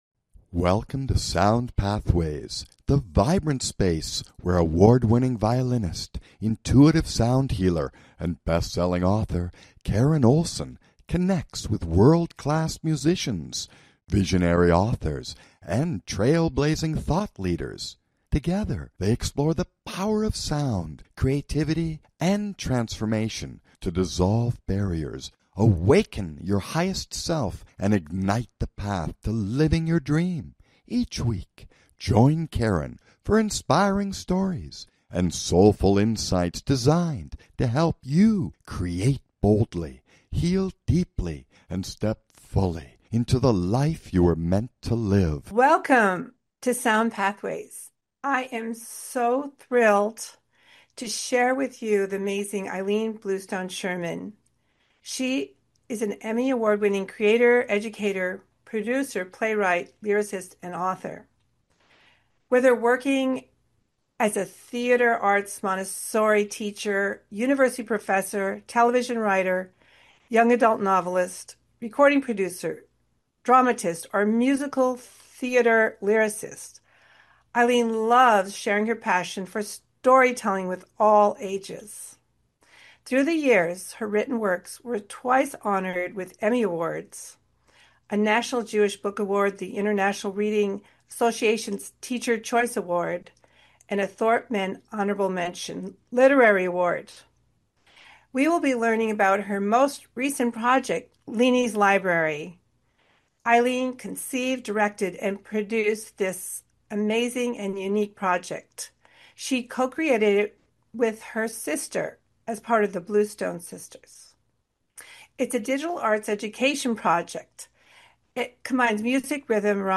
Join us for an uplifting conversation about joyful learning, community connection, and mapping your own path from inspiration to impact.